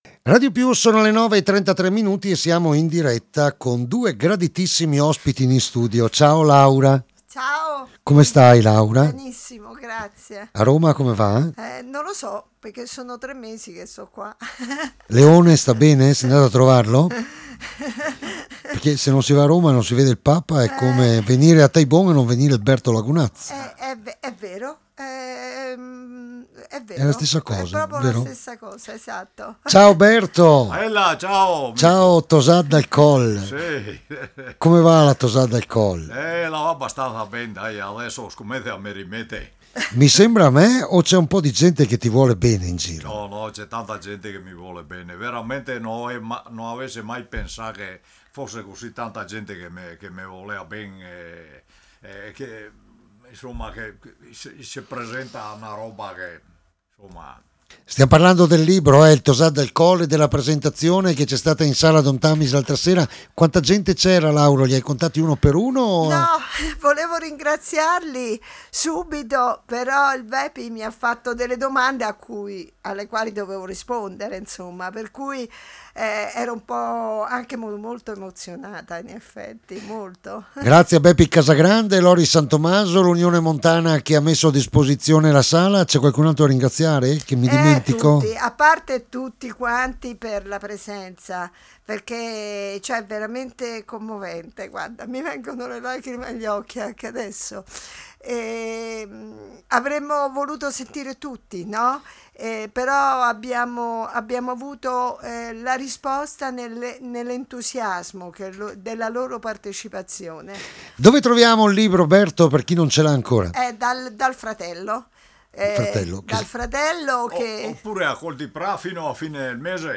*** DIRETTA RADIO